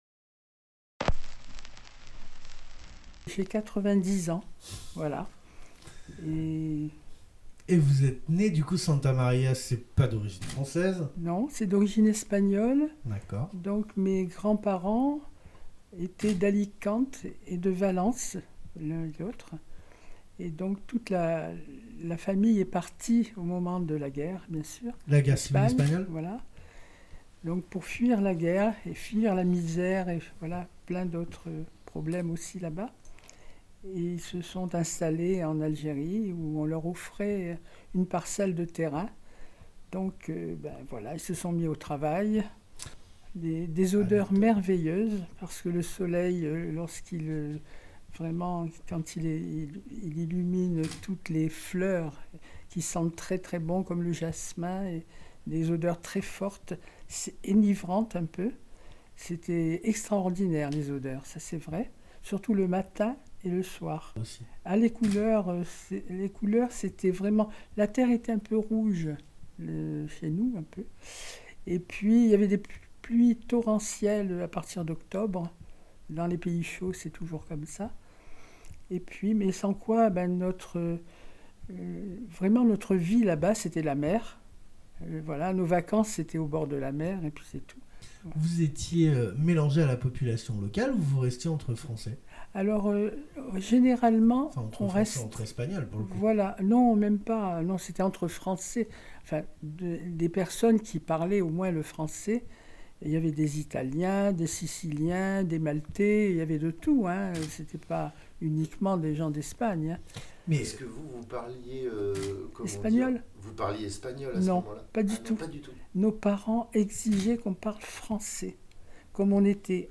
Portrait sonore